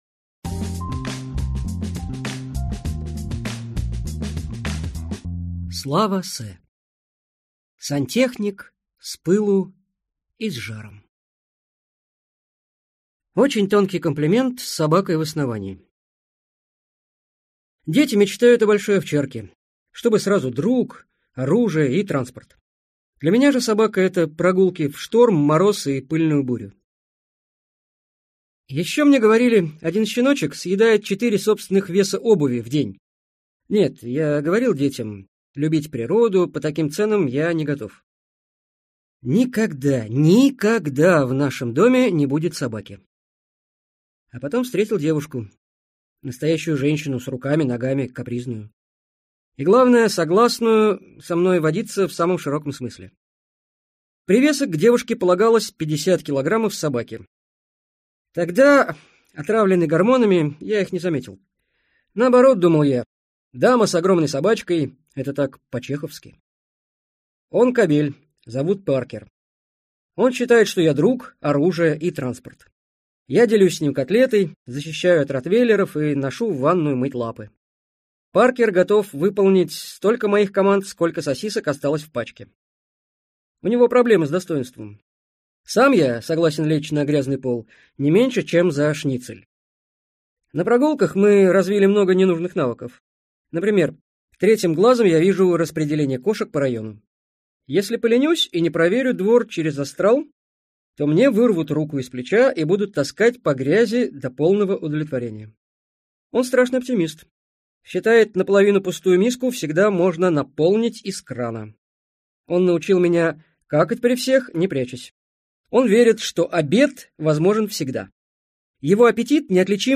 Аудиокнига Сантехник с пылу и с жаром (переиздание) | Библиотека аудиокниг